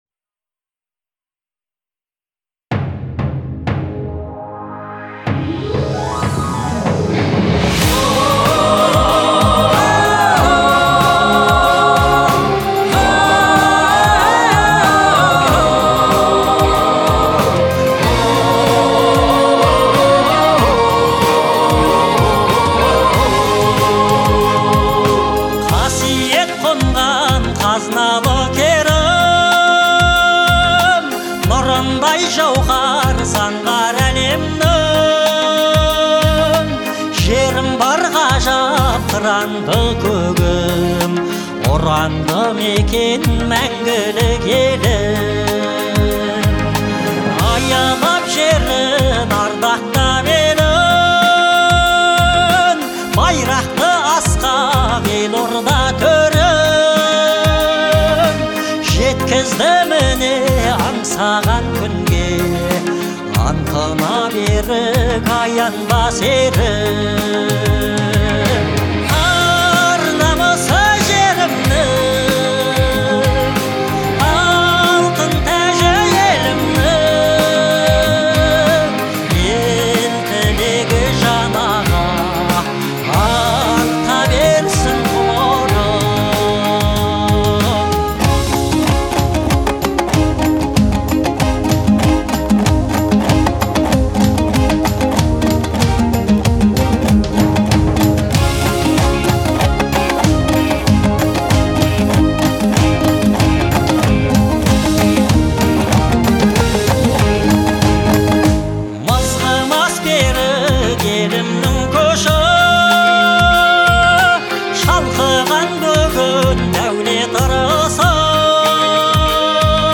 это патриотическая песня